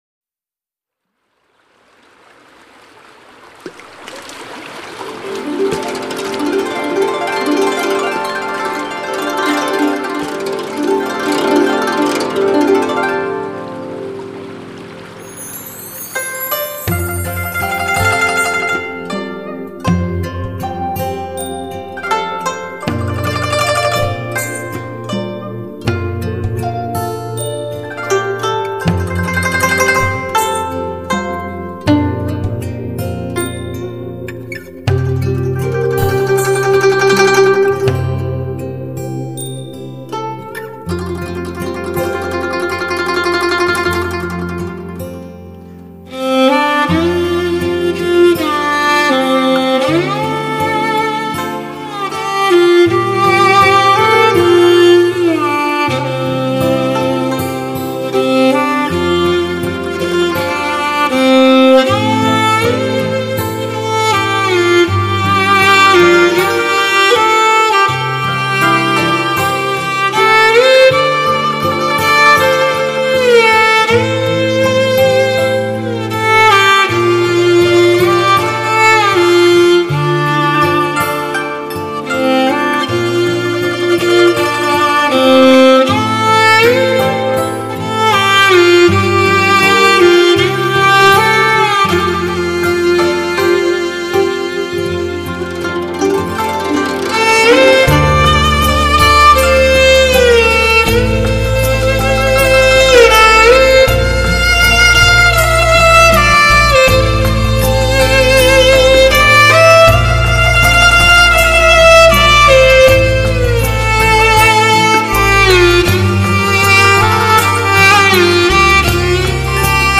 马头琴
悠悠的马头琴声，抑扬的蒙古长调，蒙古包的袅袅炊烟，远处飘来的奶茶清香，都让它在古老的马头琴声中化为轻絮。